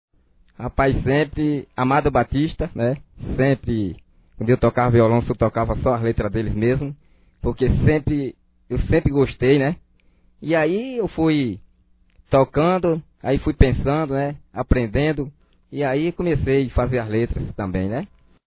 Em entrevista no programa A Voz da Comunidade que vaia ao ar todas as quarta-feira, ele responde as perguntas, ouça a seguir as reposta: